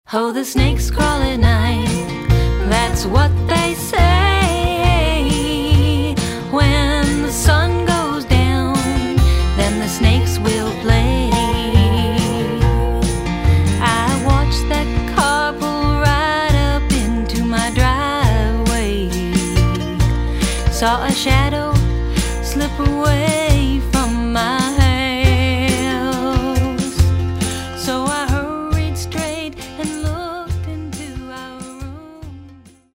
drumkit
double bass
guiotar & vocals
mandolin
guitars, banjo, fiddle, dobro, lap steel, bazooki
with instrumental overdubs at Phlosswwerx, Chicago IL